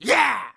monster / thief1 / attack_2.wav
attack_2.wav